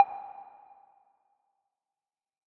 menuback.ogg